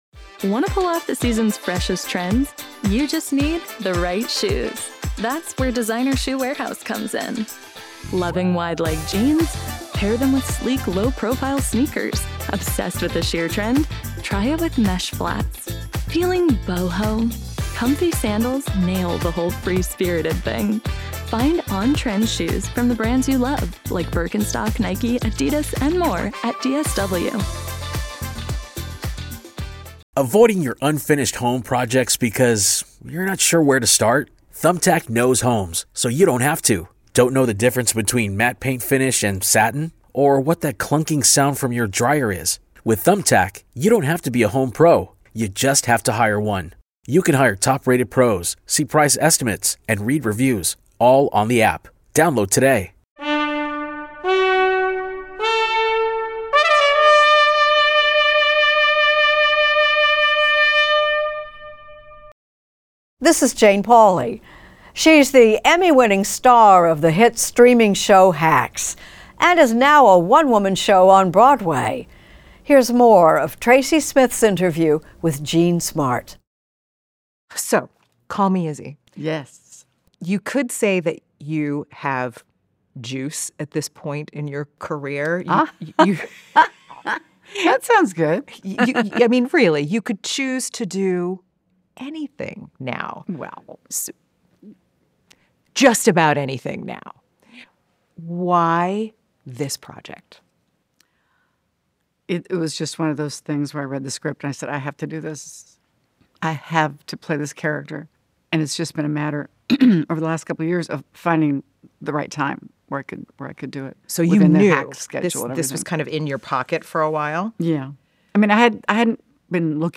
Extended Interview: Jean Smart